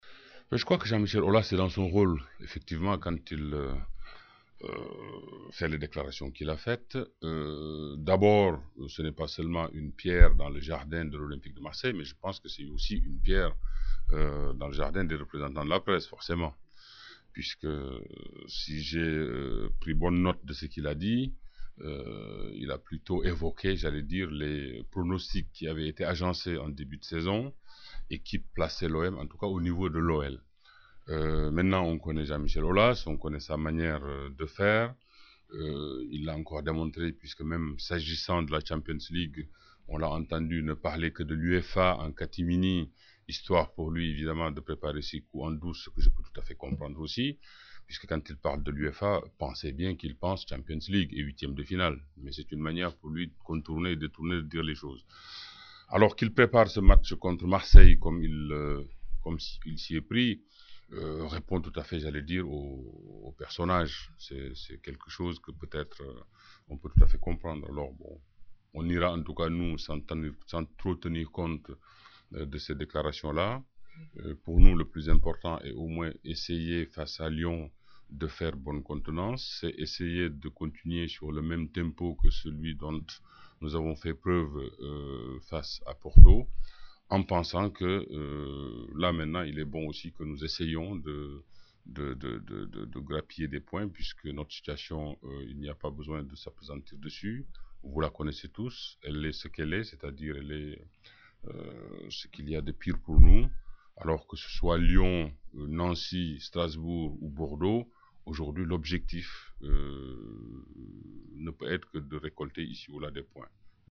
Pour ceux qui ne l’ont pas connu, parmi les plus jeunes fans de l’OM, ou les autres qui n’ont jamais entendu s’exprimer Pape Diouf dans son rôle de président de l’OM, voici quelques-unes de ses interventions parmi les plus savoureuses devant la presse à La Commanderie.